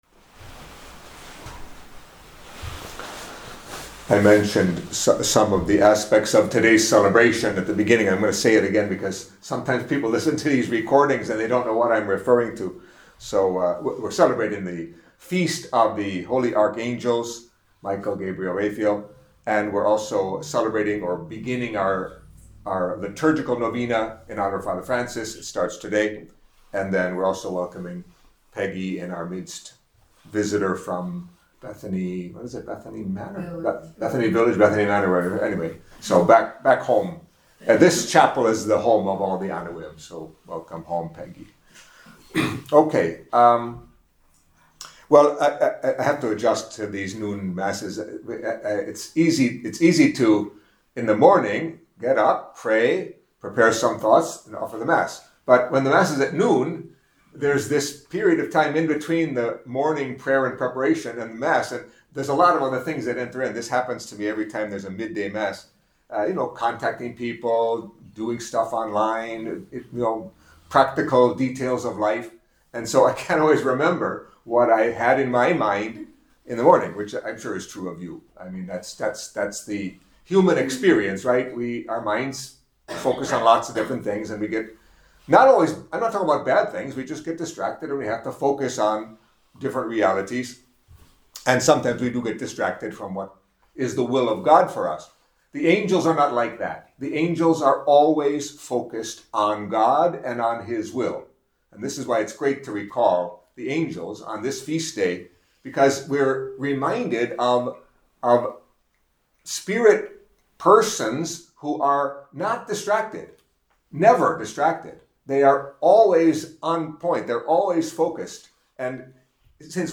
Catholic Mass homily for Feast of Saints Michael, Gabriel, and Raphael, Archangels